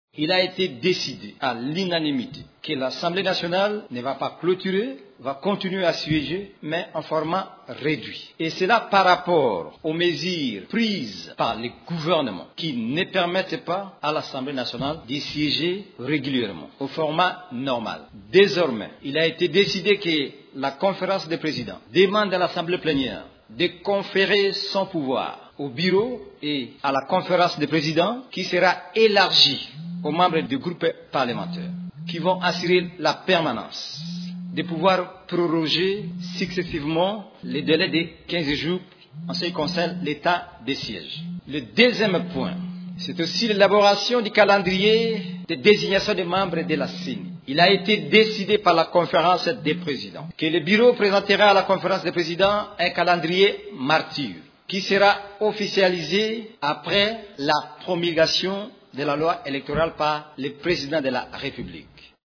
Face à l’augmentation des cas de la COVID-19, le rapporteur de la chambre basse, Joseph Lembi, précise que l’Assemblée nationale va désormais se réunir en format très réduit de 60 députés au maximum dans la salle de congrès.